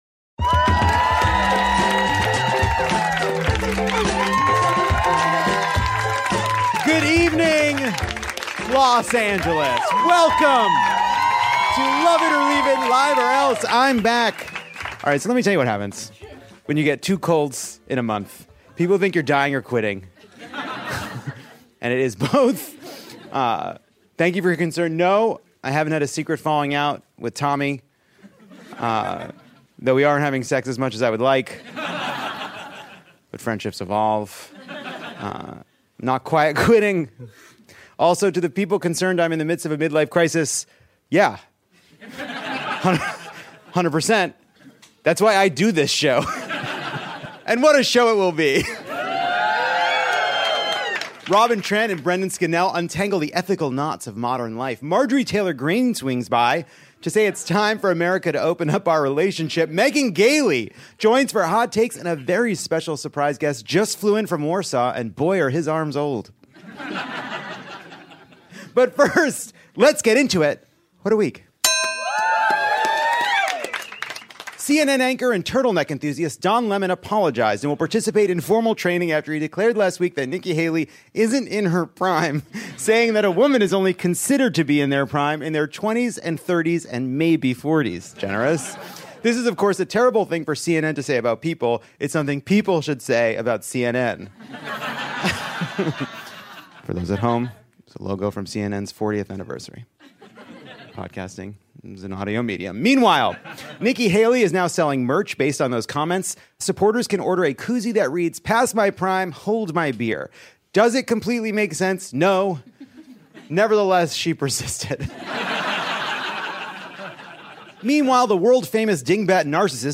In this week’s show, Lovett Or Leave It welcomes President Biden to the Dynasty Typewriter stage to address all these audio deepfakea of him on TikTok. At least it sure sounds like President Biden?
Our audience tries to guess which linguistic worms got yoinked out of Roald Dahl’s literary peaches, while our guests’ Hot Takes warm our hearts on this chilly, rainy Los Angeles night.